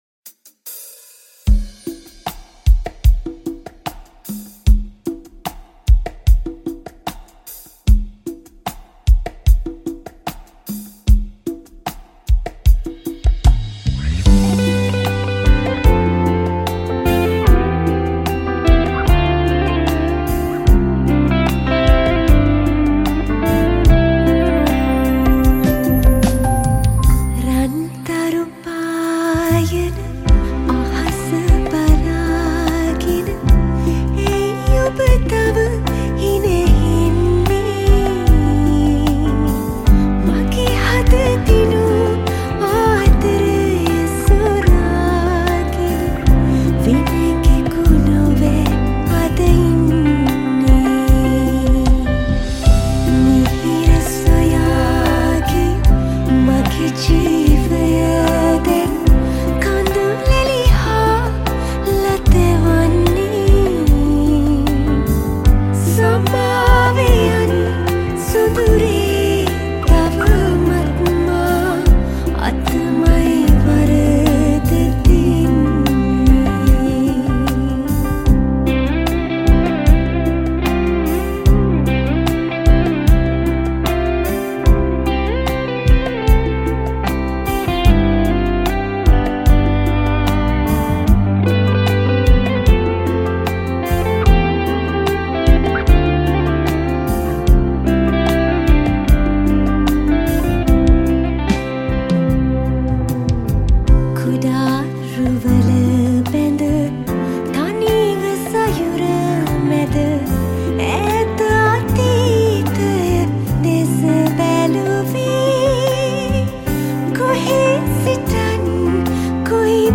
High quality Sri Lankan remix MP3 (5).